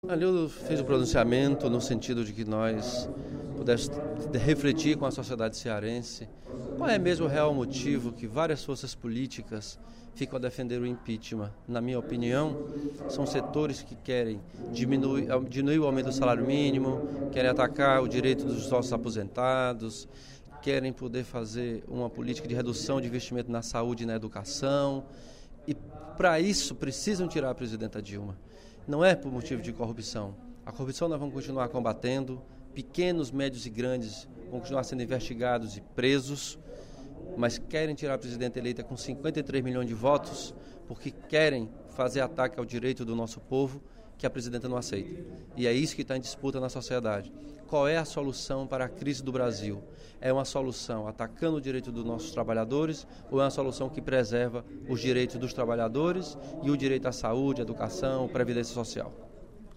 O deputado Elmano Freiras (PT) usou o segundo expediente da sessão plenária desta sexta-feira (18/09) para comentar a real motivação das forças políticas que pedem o impeachment da presidente Dilma Rousseff na Câmara dos Deputados.